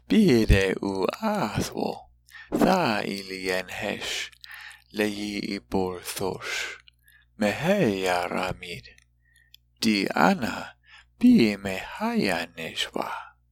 When paragraphs of Láadan are given, sound files will be provided for the entire paragraph as well as each sentence.